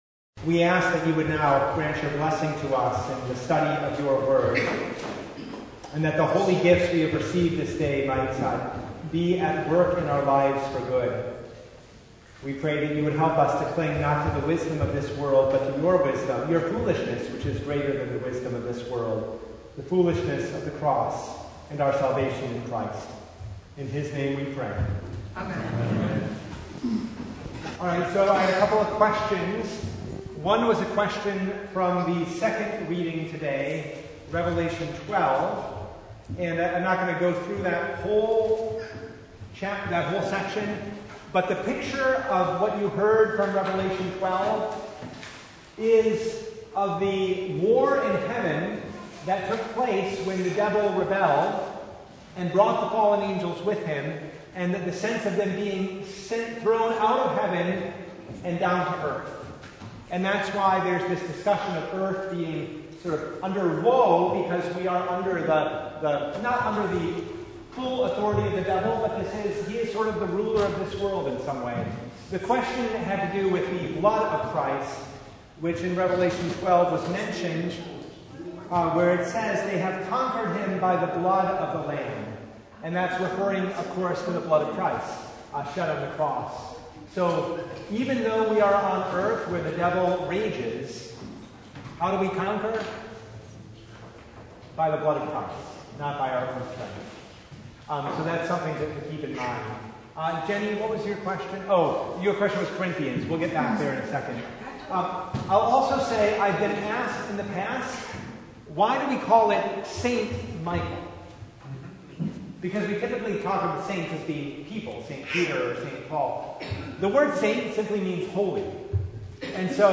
1 Corinthians 1:18-29 Service Type: Bible Hour Topics: Bible Study « Glimpses of the Unseen The Second Sunday in Angels’ Tide